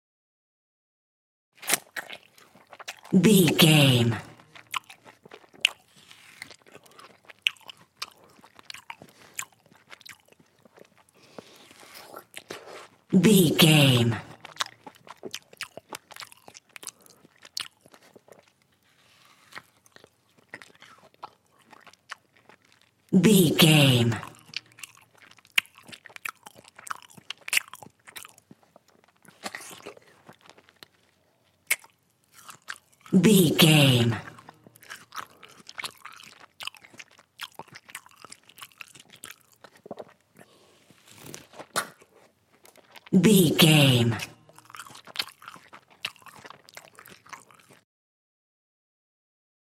Eating juicy food
Sound Effects
foley